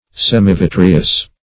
Semivitreous \Sem`i*vit"re*ous\, a.